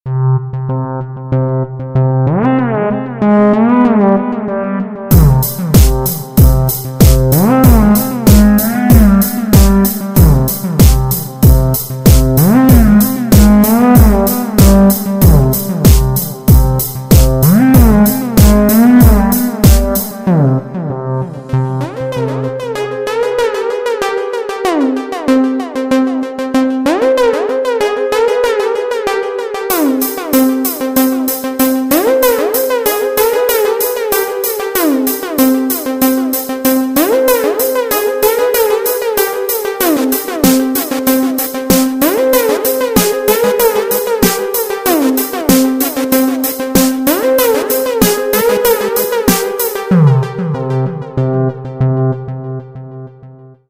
Electronic
EDM
мощные басы
качающие
космические
Trance
загадочные
Загадочный космический звонок...